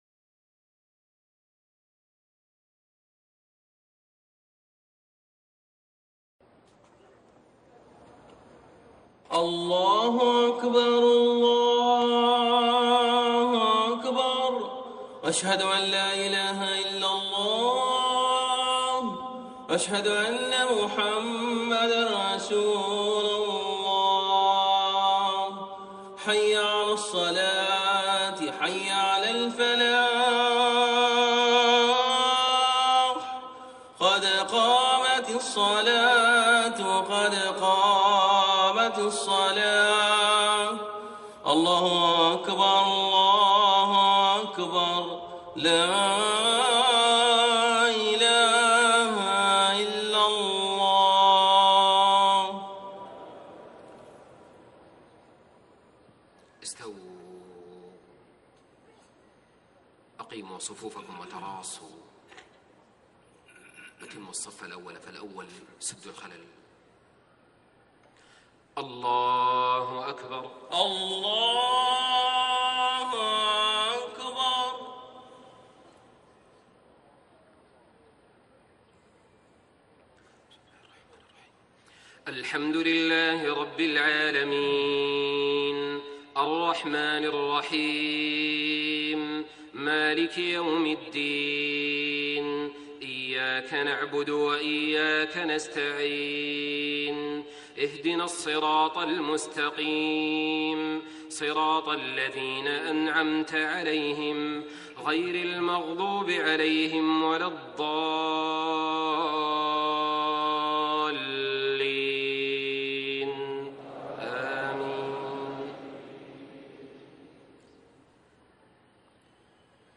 صلاة الجمعة 4-3-1433هـ سورتي الزلزلة و العاديات > 1433 🕋 > الفروض - تلاوات الحرمين